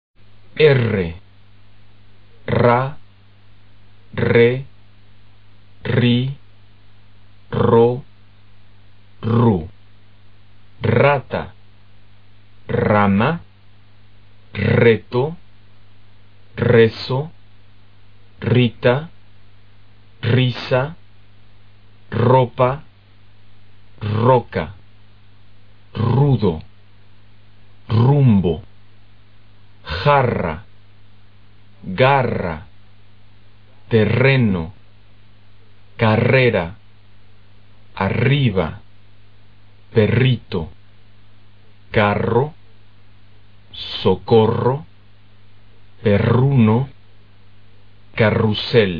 RR的发音】
在词首或n,l,s之后，应当发成多击颤音。此外，rr也是多击颤音。多击颤音的发音部位和方法与单击颤音r相同，只是舌尖需要颤动许多次。